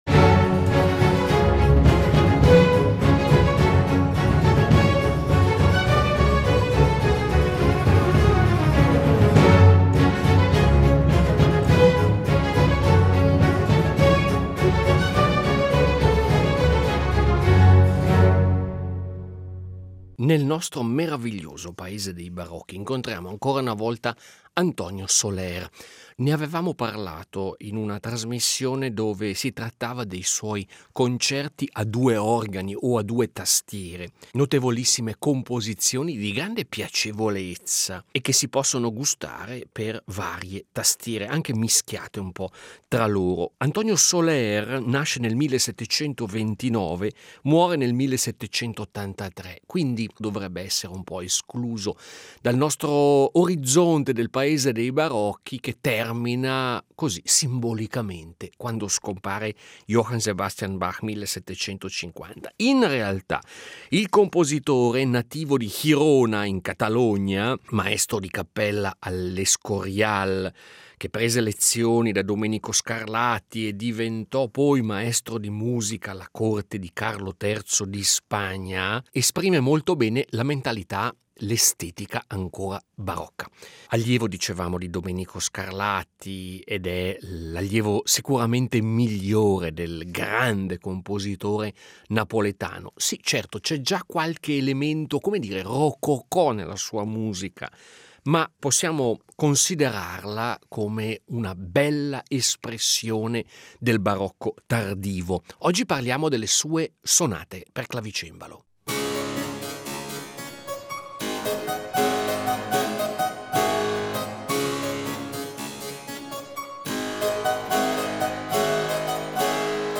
Uno scatenato Soler al cembalo